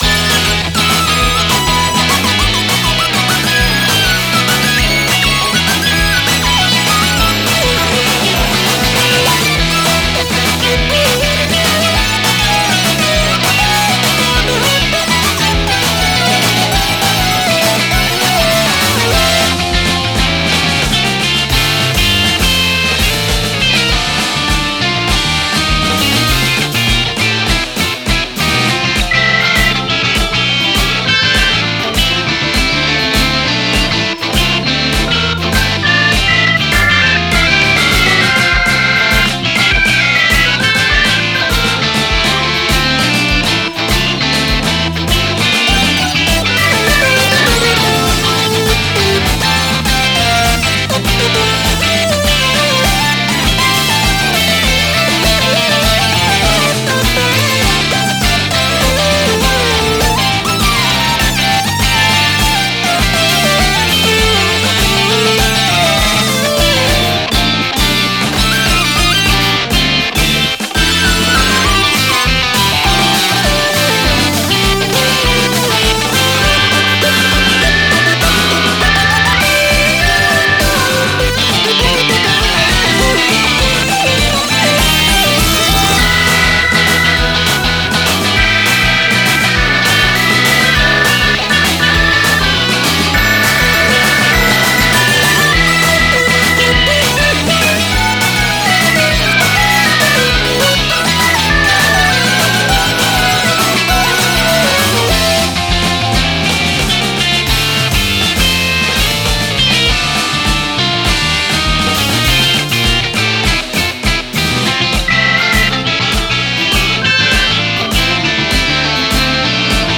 明るい雰囲気の曲です。
エレキギター君どうした
タグ あかるい